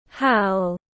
Tiếng hú tiếng anh gọi là howl, phiên âm tiếng anh đọc là /haʊl/
Howl /haʊl/